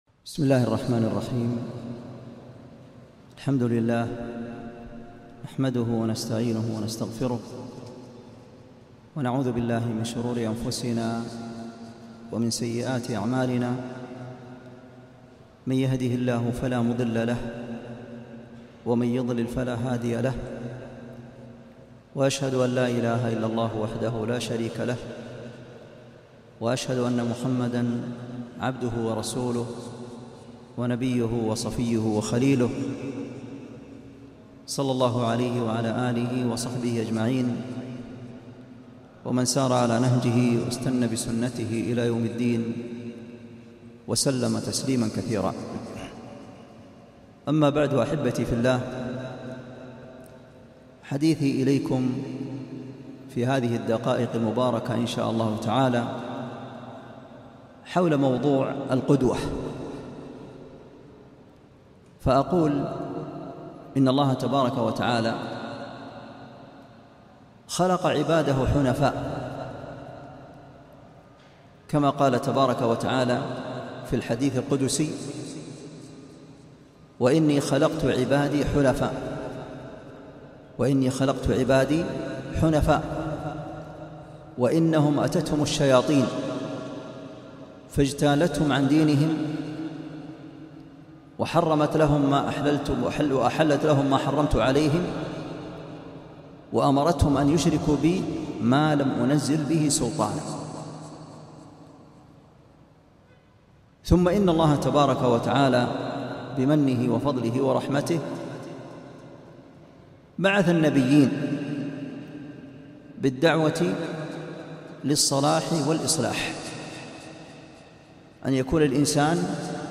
محاضرة - الـقـدوة